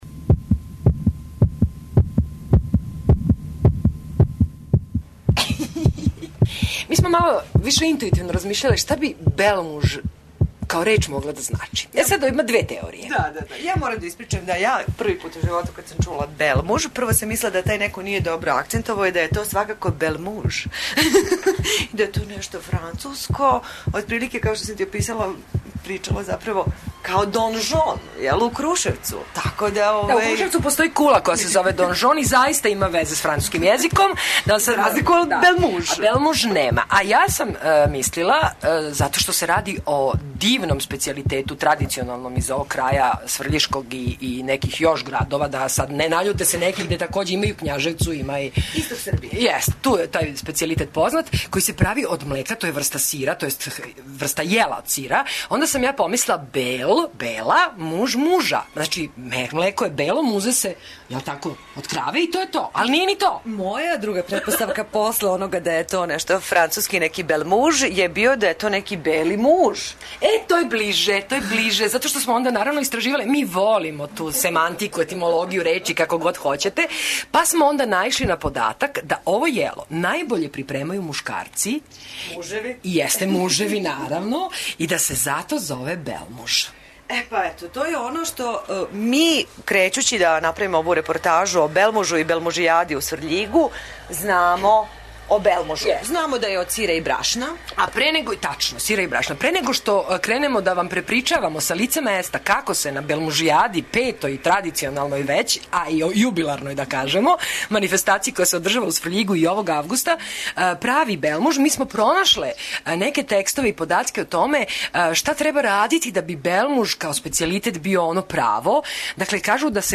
Екипа Пулса била је у Сврљигу на 6. Белмужијади где су се представили најбољи мајстори у прављењу овог специјалитета од младог овчијег сира и кукурузног брашна, као и најталентованији произвођачи.